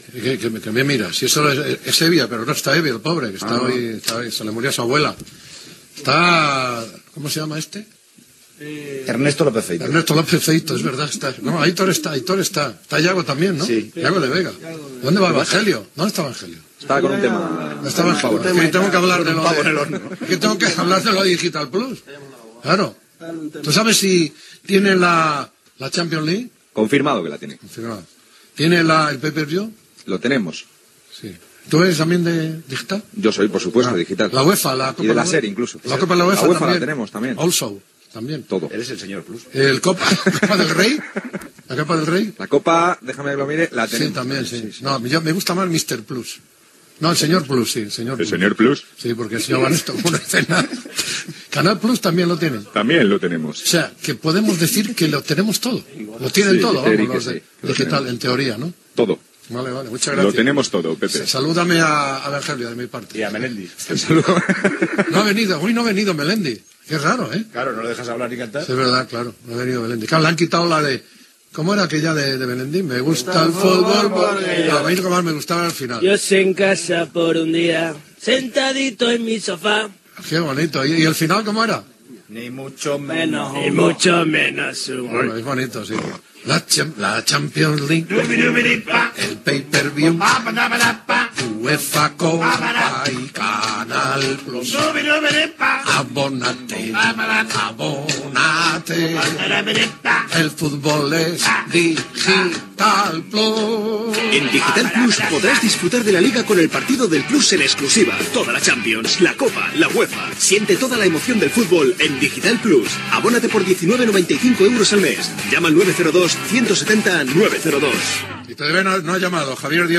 Publicitat de la plataforma Digital +
Esportiu